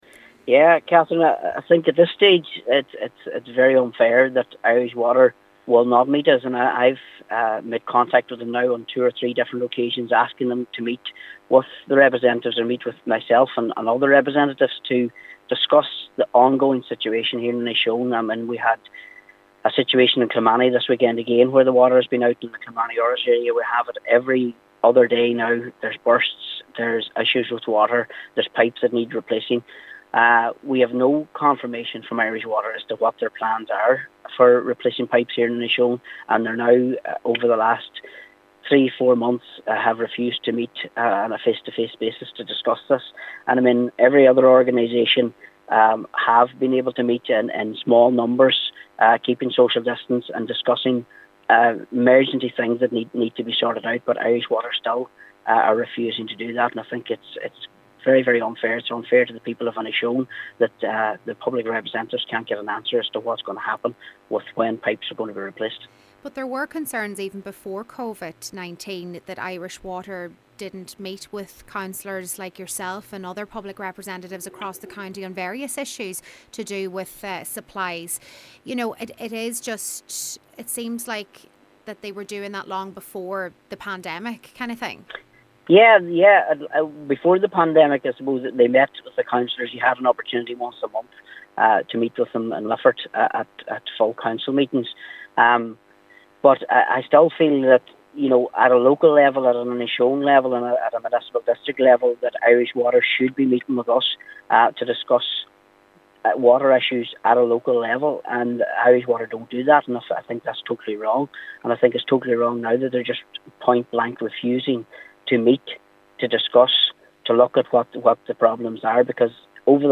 Cllr McDermott says all they want are answers: